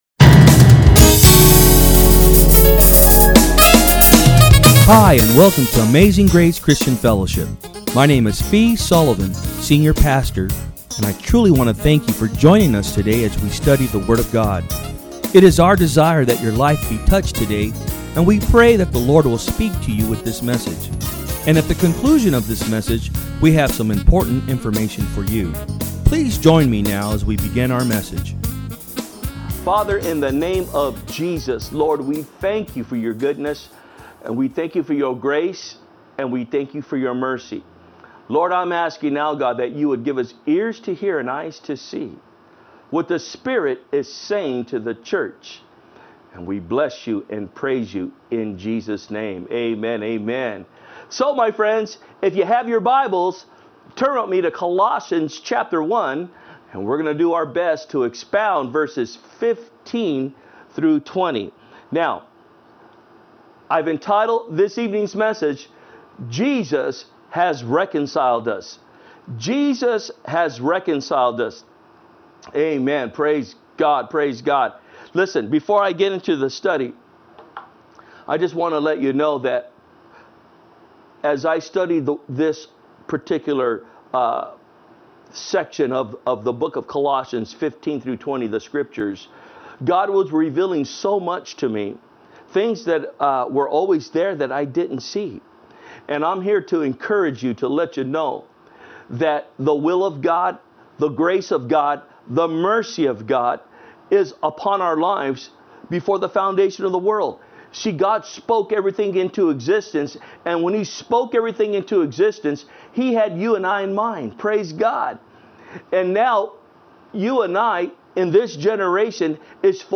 From Service: "Wednesday Pm"